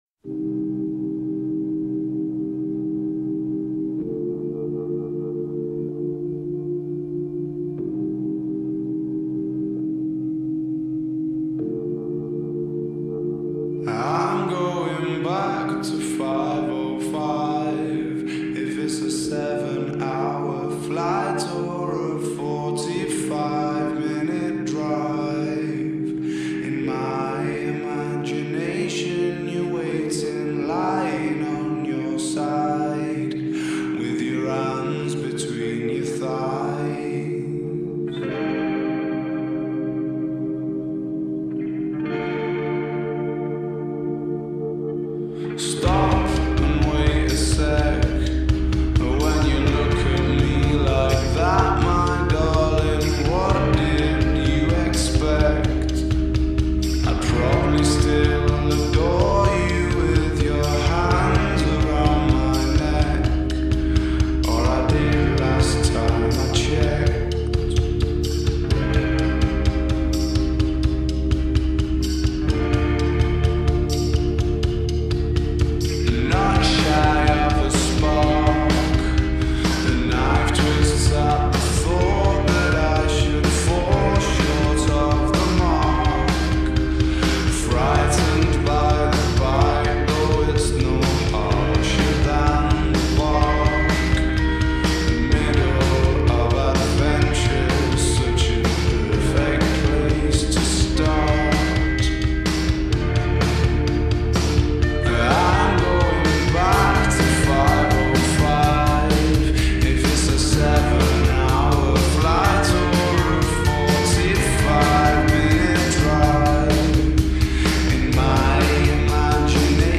نسخه کند شده و Slowed
غمگین